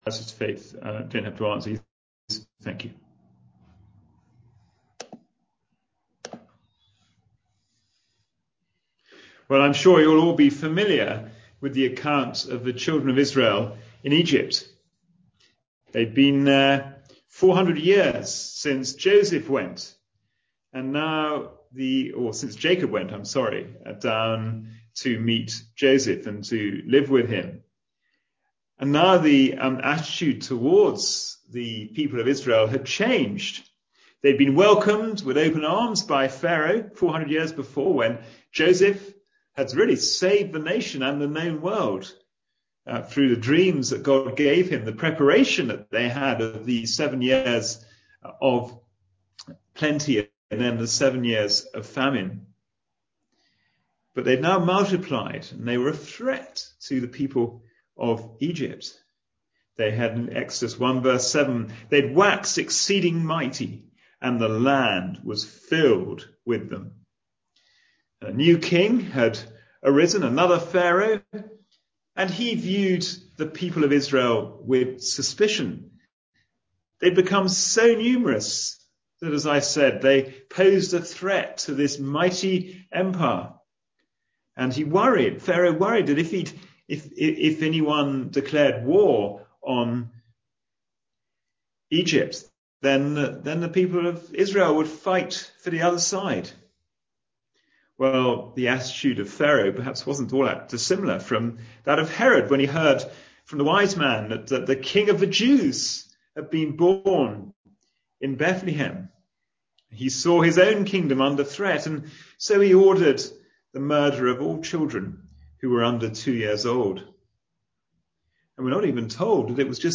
Passage: Exodus 5:1-18 Service Type: Sunday Evening Service